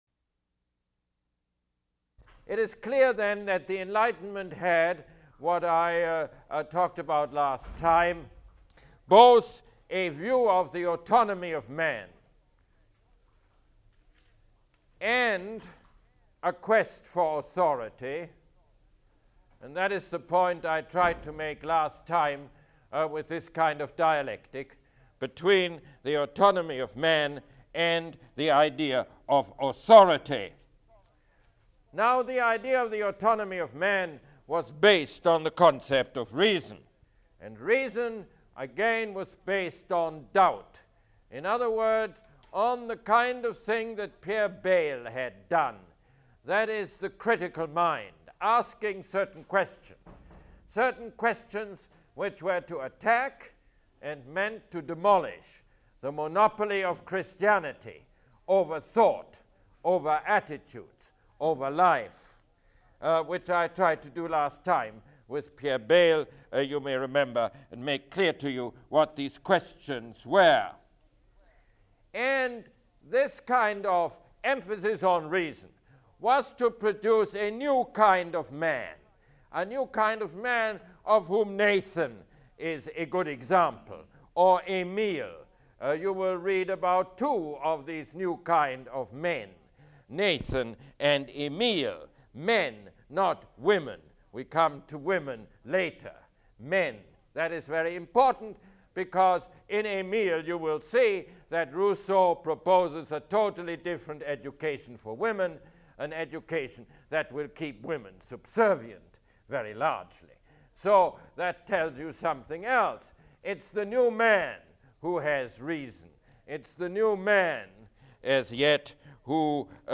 Mosse Lecture #5